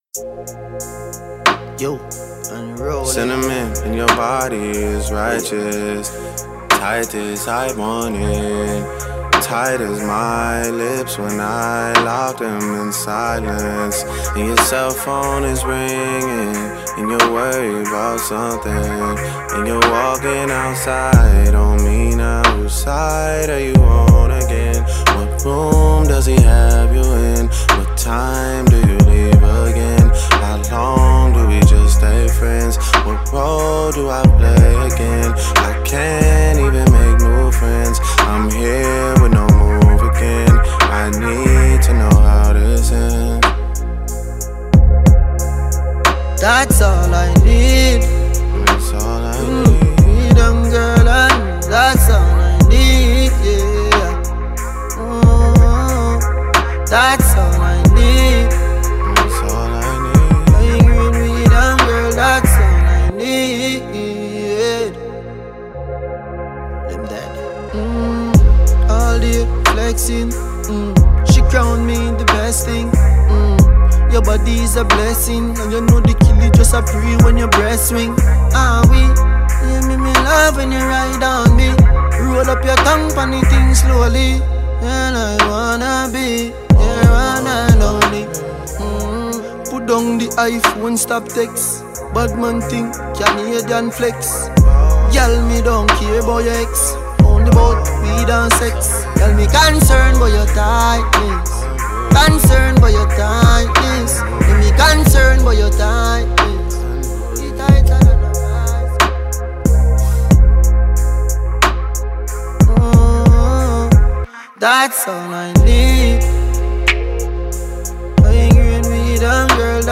Dancehall/HiphopMusic
Multi-talented Jamaican dancehall musician